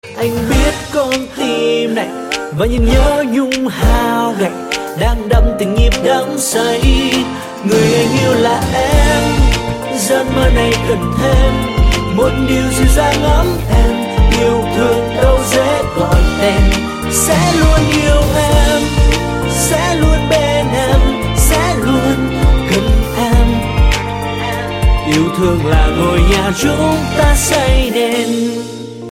Thể loại nhạc chuông: Nhạc trẻ HOT